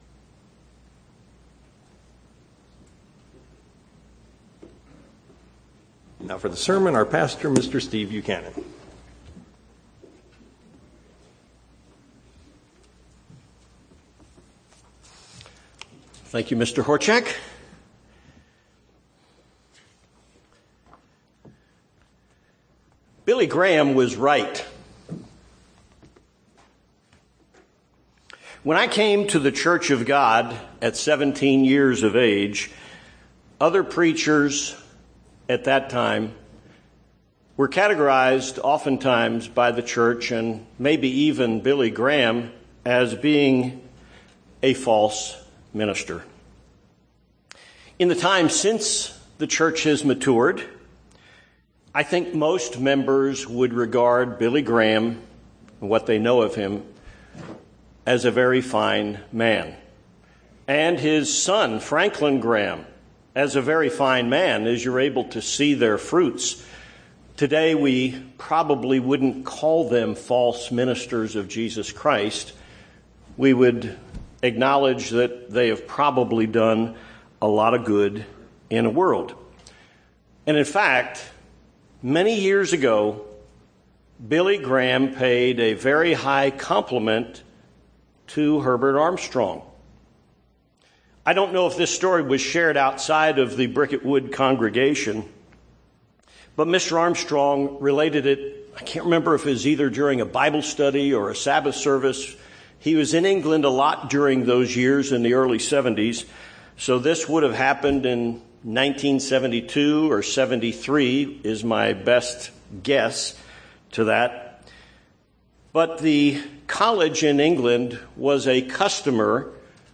The Church of God has a rich heritage in understanding an important topic: Bible Prophecy. This sermon presents 2 keys that help us understand the future.